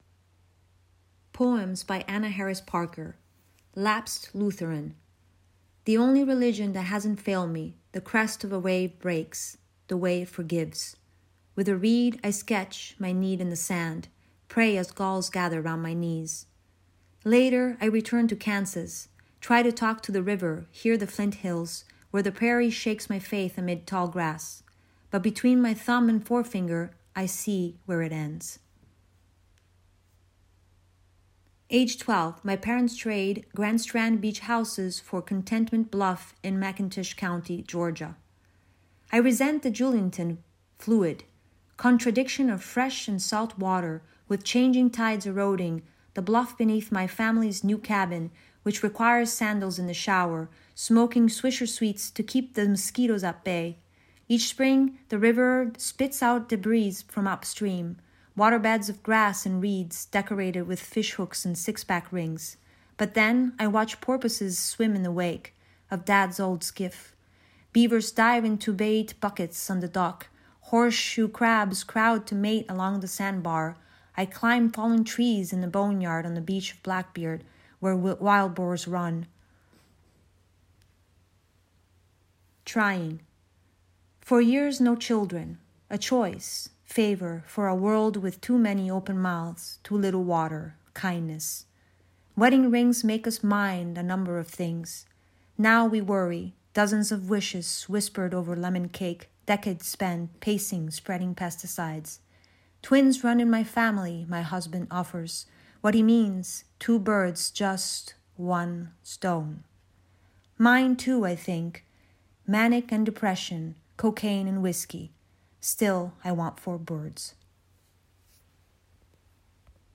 readings from our Online Podcast: